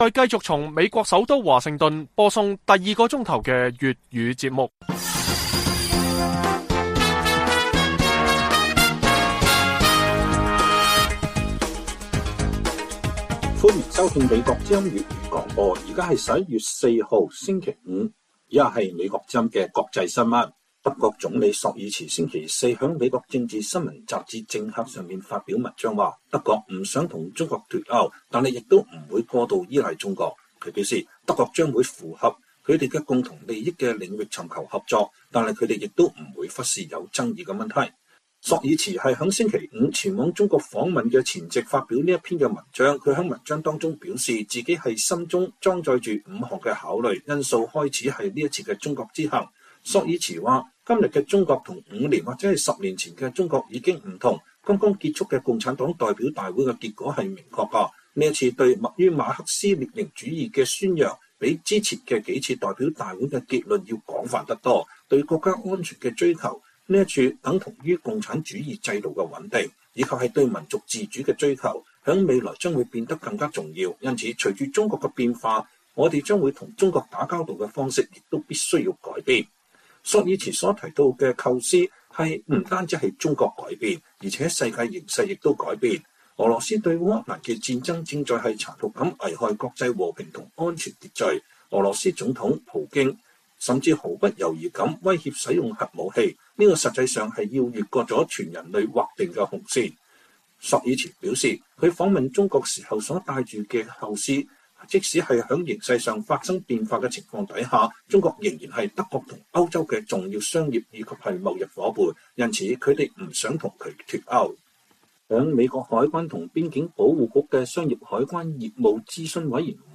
粵語新聞 晚上10-11點: 德國總統李朔爾茨說柏林不想與北京脫鉤，但也不會過度依賴北京